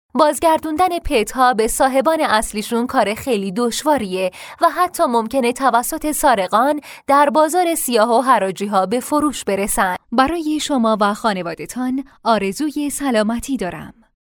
Female
Young
Adult